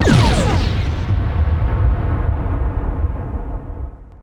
disruptor.ogg